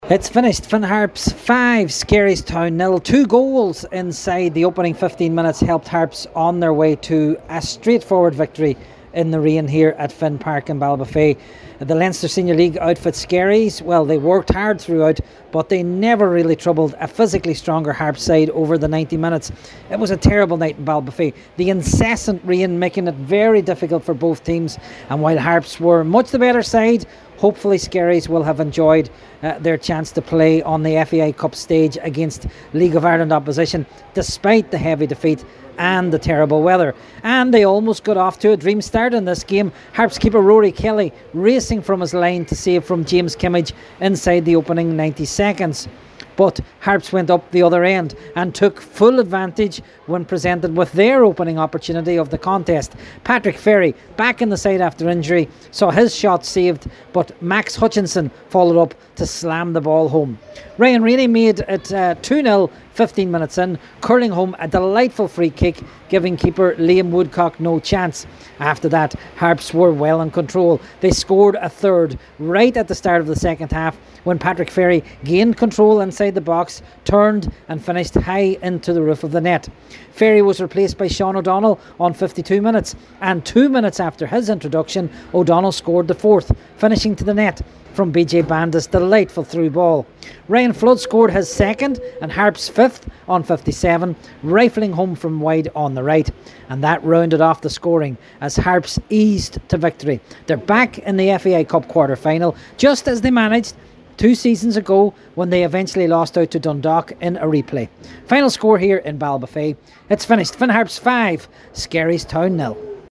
FT Report: Finn Harps 5 Skerries Town 0
Harps-full-time-report-.mp3